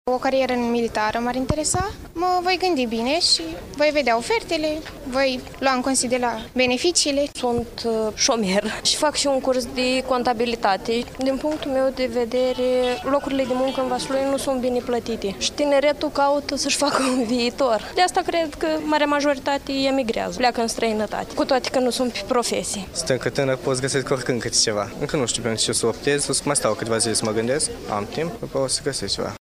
19-oct-rdj-17-vox-pop-absolventi-VS.mp3